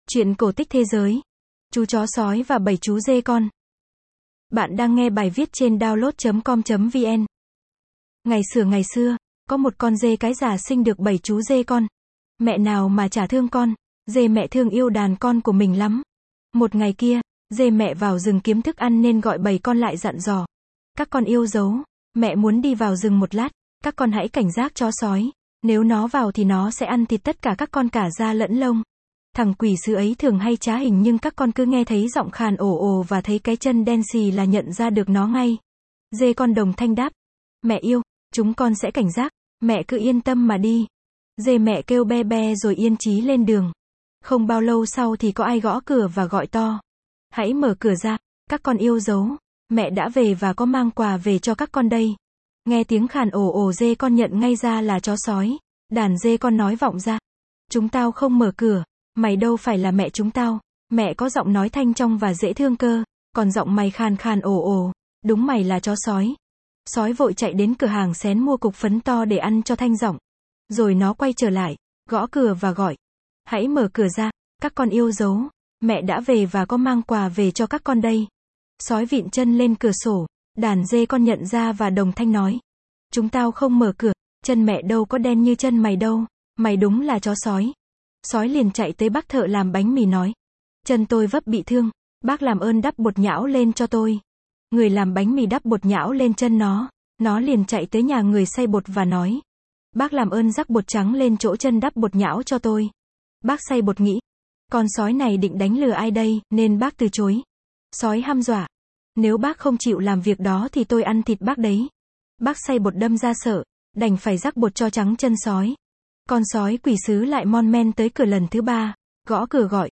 Sách nói | Chú chó sói và bảy chú dê con